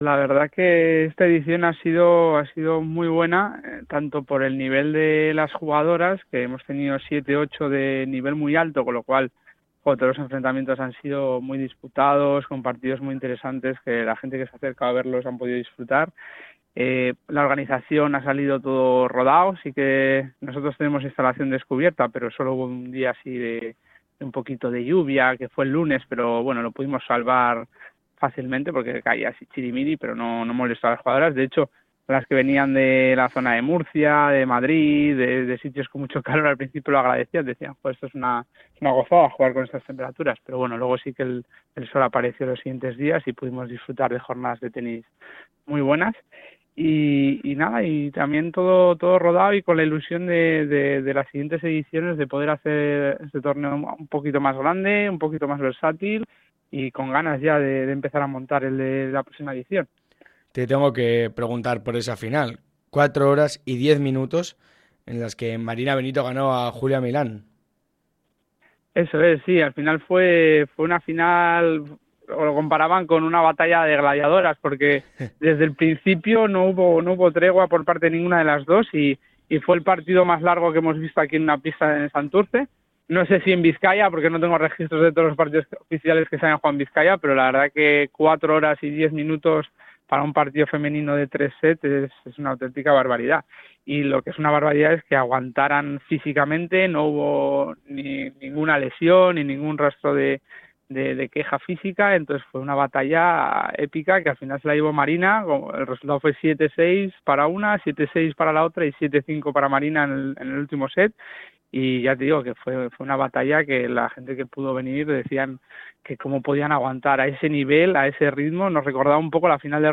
TORNEO-SANTURTZI-ENTREVISTA.mp3